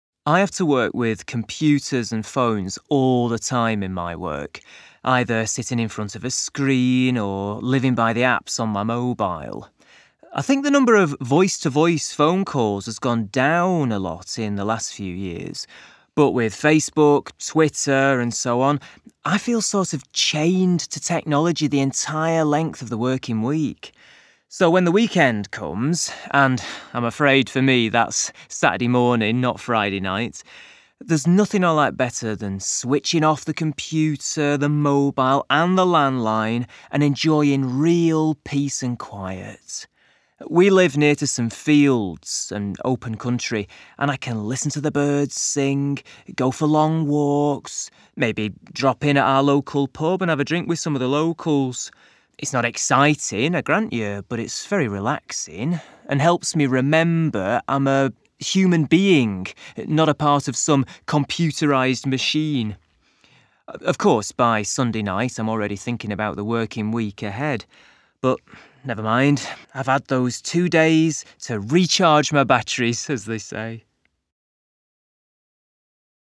This activity provides short listening practice based around a monologue regarding technology.